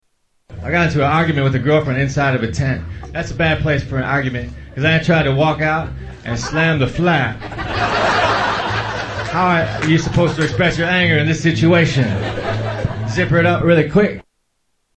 Category: Comedians   Right: Personal
Tags: Comedians Mitch Hedberg Sounds Mitchell Lee Hedberg Mitch Hedberg Clips Stand-up Comedian